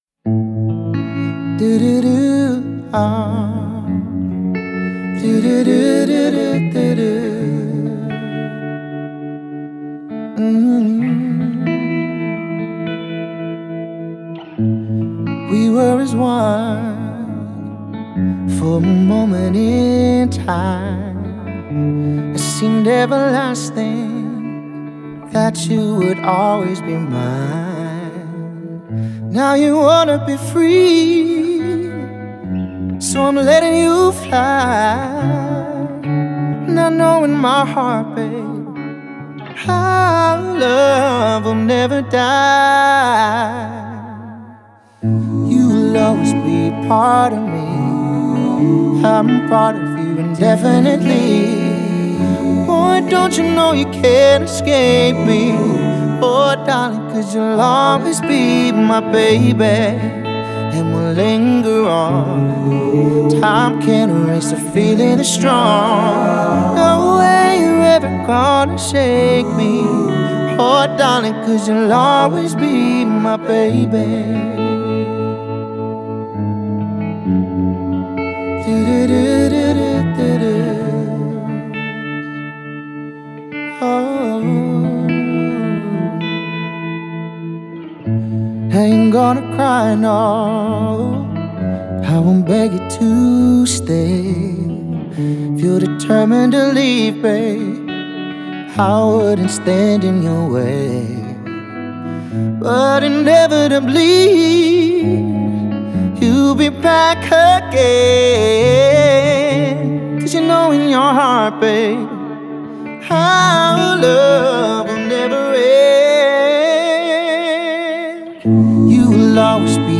Vocals
With his soulful vocals and electrifying presence